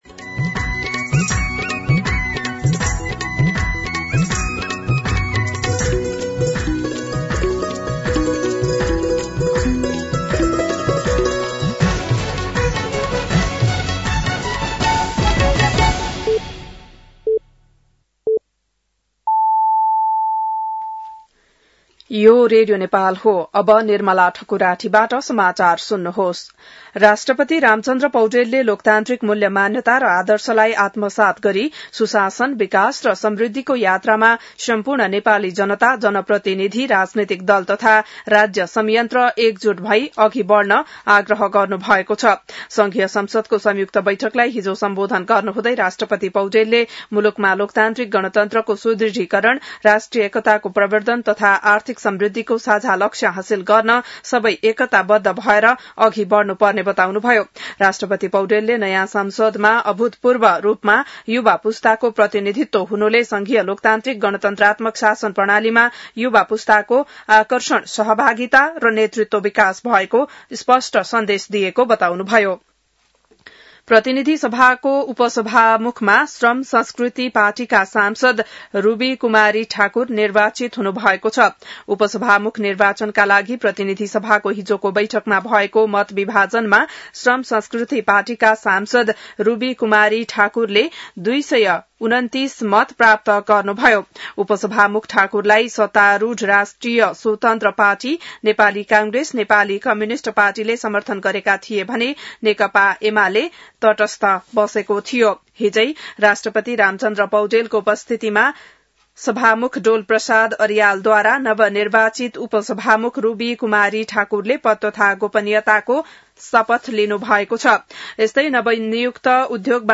बिहान ११ बजेको नेपाली समाचार : २८ चैत , २०८२